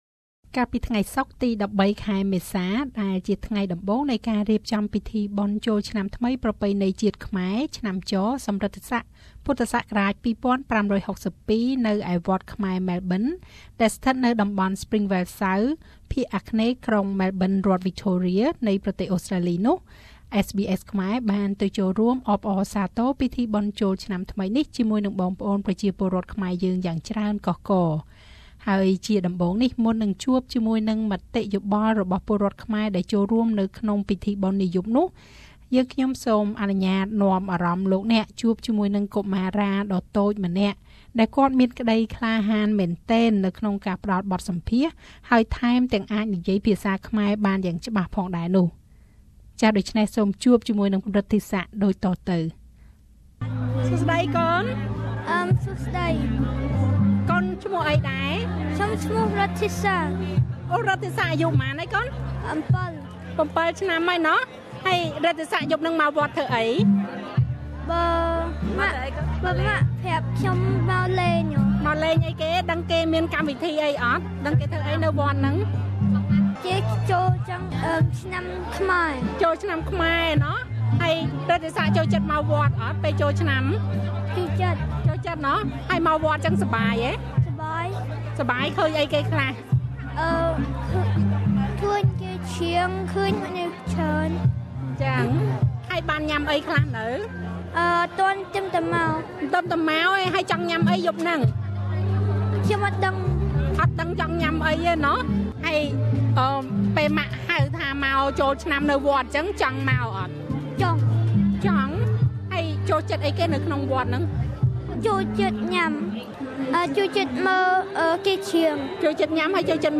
ពាក្យពេចន៍ជូនពរឆ្នាំថ្មីរបស់ពលរដ្ឋខ្មែរដែលចូលរួមពិធីបុណ្យចូលឆ្នាំថ្មីនៅវត្តខ្មែរម៉ែលប៊ន
កាលពីថ្ងៃសុក្រទី១៣មេសាដែលជាថ្ងៃដំបូងនៃការរៀបចំពិធីបុណ្យ ចូលឆ្នាំថ្មីប្រពៃណីជាតិខ្មែរ ឆ្នាំច សំរិទ្ធិស័ក ពស ២៥៦២ នៅឯវត្តខ្មែរម៉ែលប៊ិន ដែលស្ថិតនៅតំបន់ Springvale South ភាគអគ្នេយ៍ក្រុងម៉ែលប៊ិន រដ្ឋវិចថូរៀនៃប្រទេសអូស្រ្តាលី SBS ខ្មែរបានទៅចូលរួមអបអរសាទរពិធីបុណ្យចូលឆ្នាំថ្មីនេះជាមួយបងប្អូនប្រជាពលរដ្ឋខ្មែរយើងយ៉ាងច្រើនកុះករ។